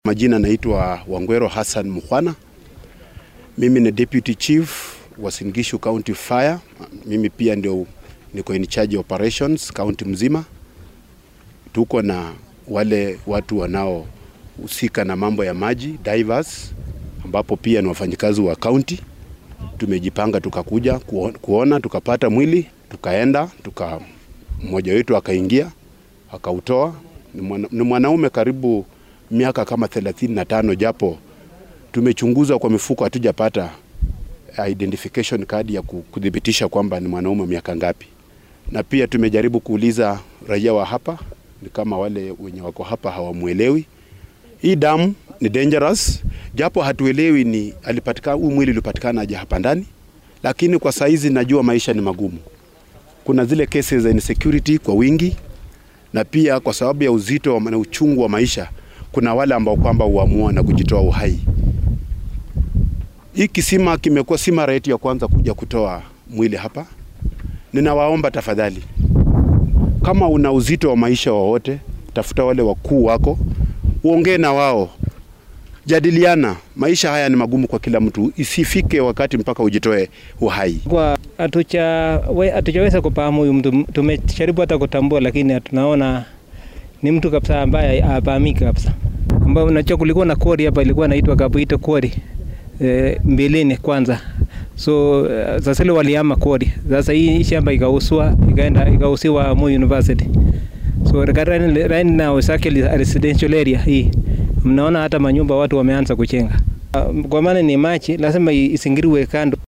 SOUND-BITE-FIRE-DEPARTMENT-.mp3